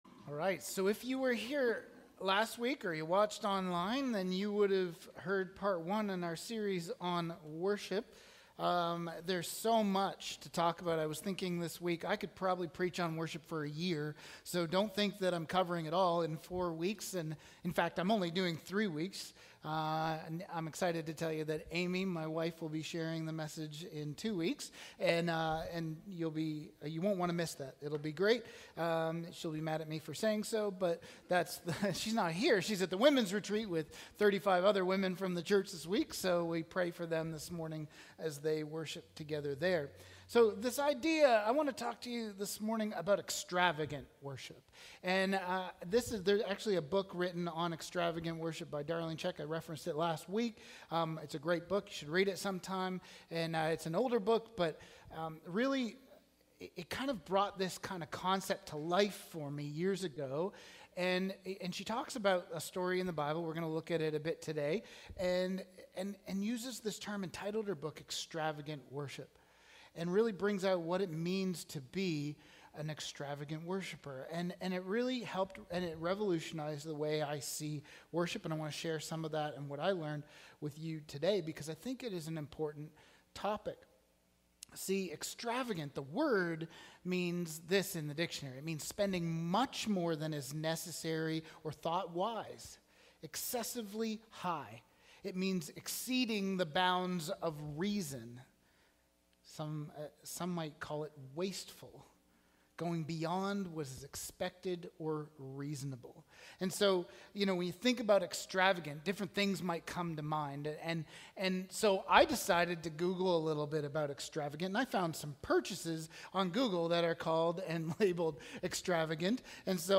Sermons | Sunnyside Wesleyan Church